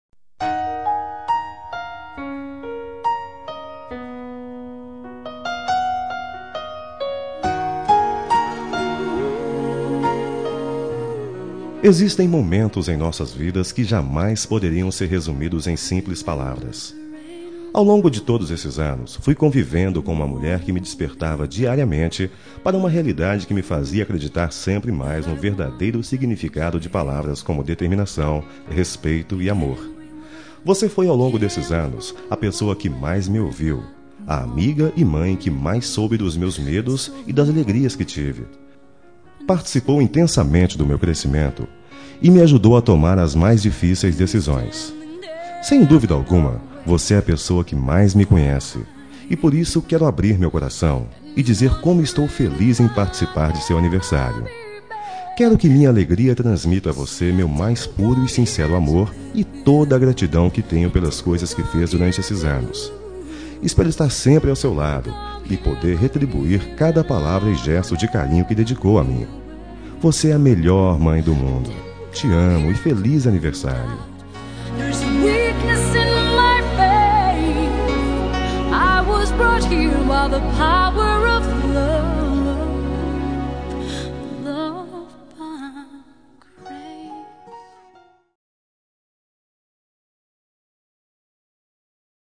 Telemensagem de Aniversário de Mãe – Voz Masculina – Cód: 1431 – Linda